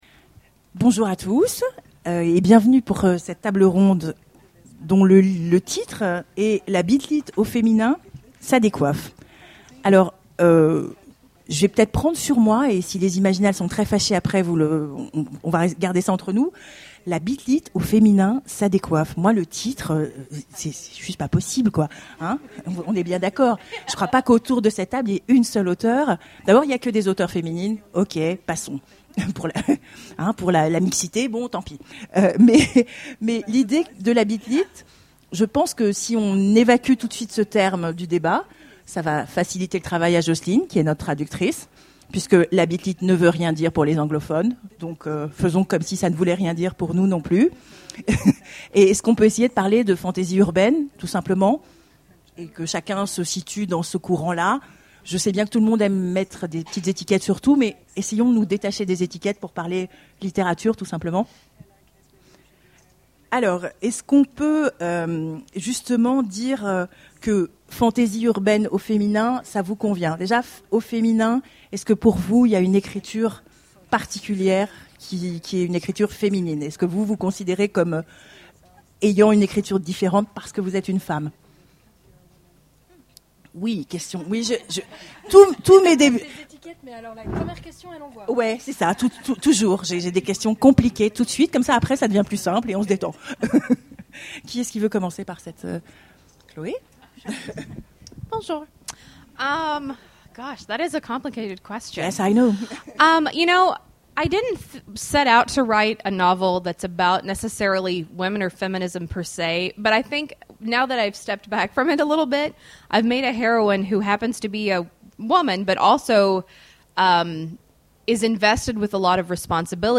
Imaginales 2014 : Conférence La bit-lit au féminin
Conférence